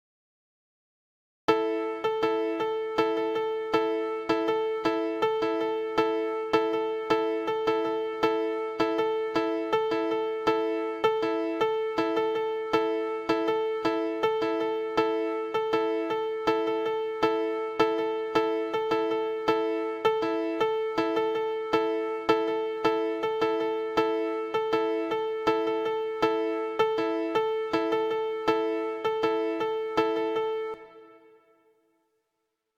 The top part follows the bottom part which is the beat.